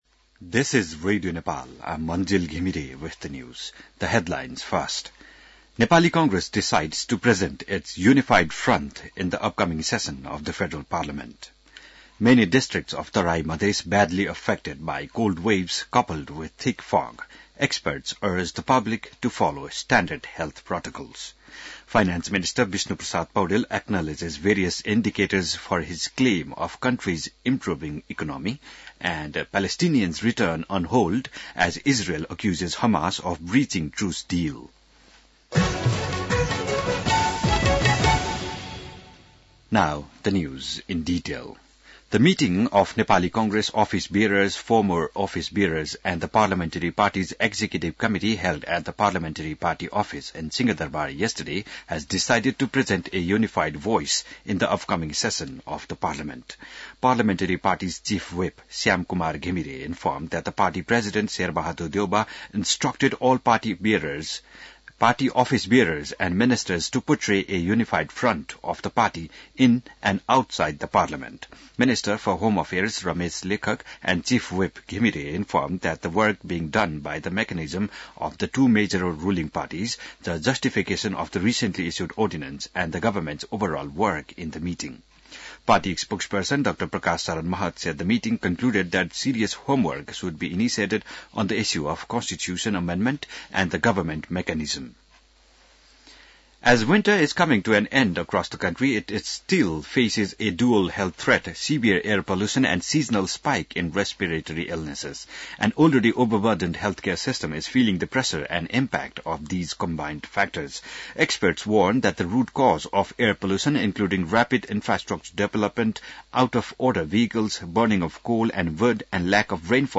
बिहान ८ बजेको अङ्ग्रेजी समाचार : १४ माघ , २०८१